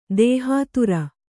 ♪ dēhātirikta